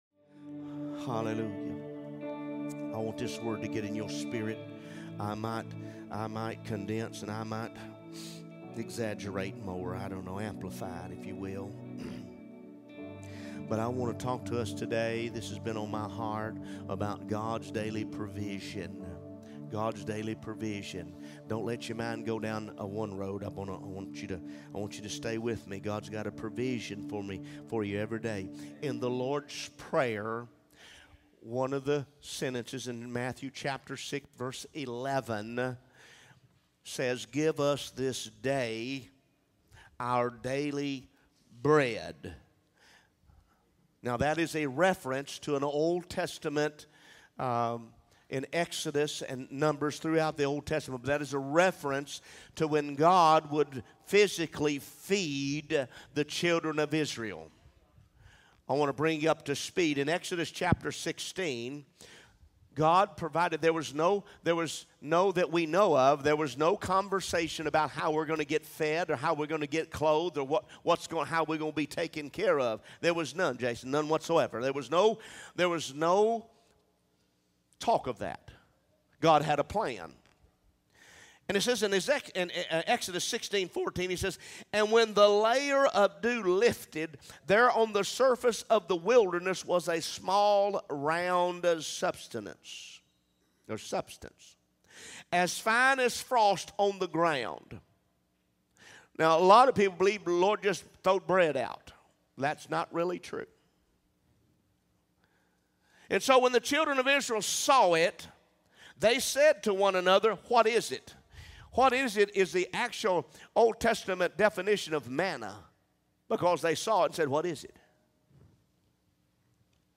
A message from the series "Sunday Message."